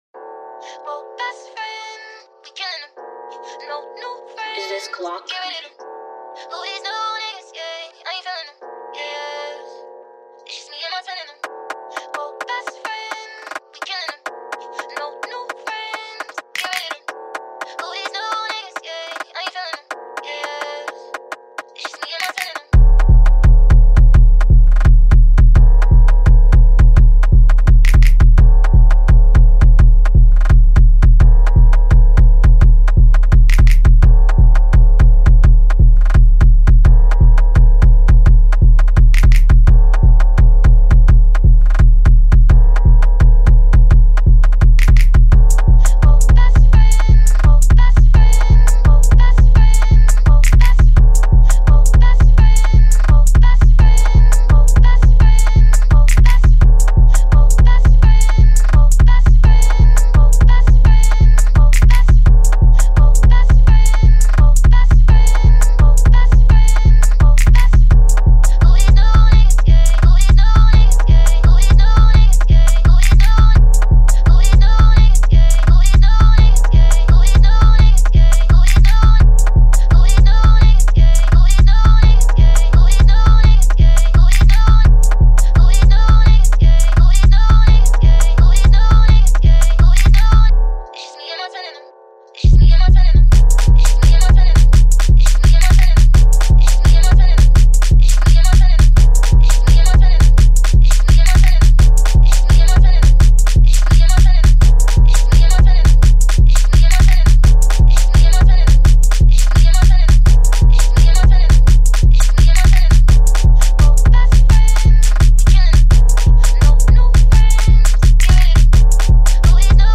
Here's the official instrumental
2022 in NY Drill Instrumentals